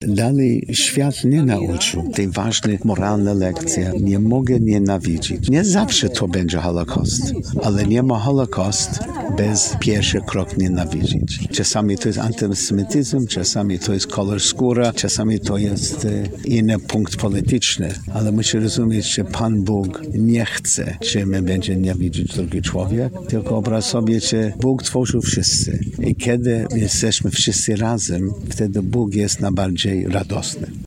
Ceremonia Pamięci związana z 81. rocznicą likwidacji niemieckiego nazistowskiego obozu koncentracyjnego na Majdanku odbyła się w Lublinie.